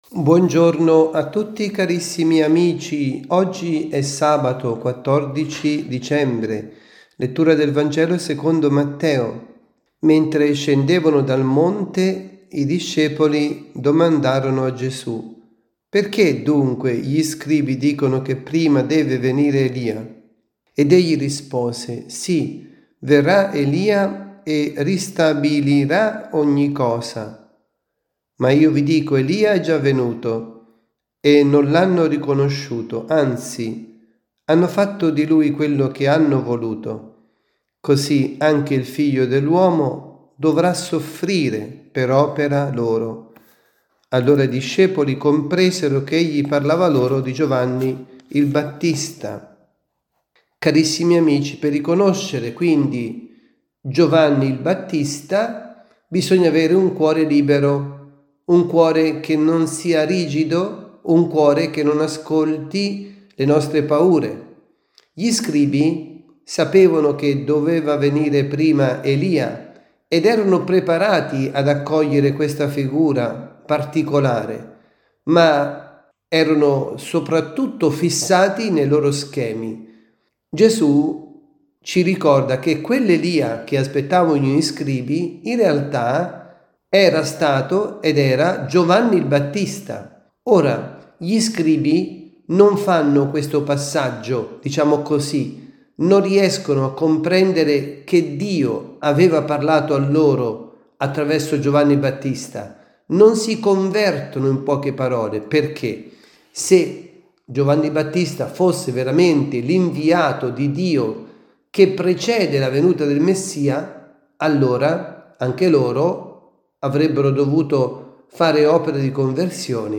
Avvento, avvisi, Catechesi, Omelie